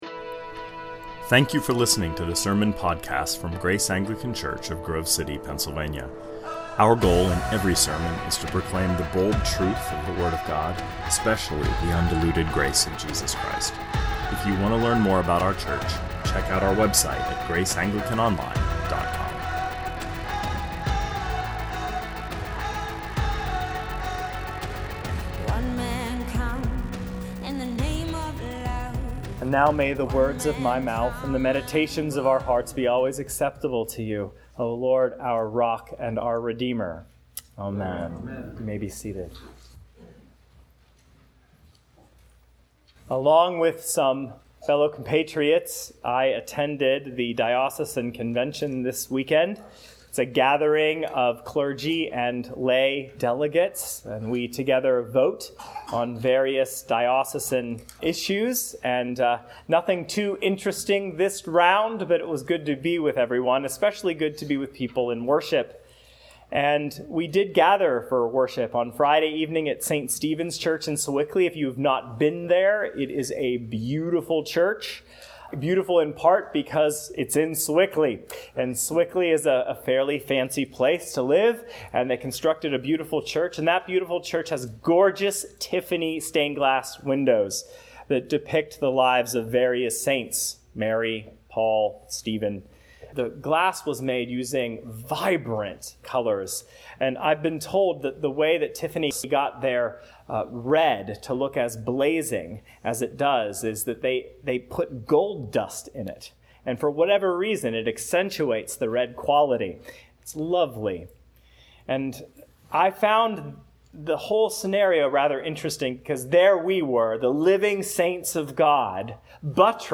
2019 Sermons All Saints and the Blood Fountain -Revelation 7 Play Episode Pause Episode Mute/Unmute Episode Rewind 10 Seconds 1x Fast Forward 30 seconds 00:00 / 28:41 Subscribe Share RSS Feed Share Link Embed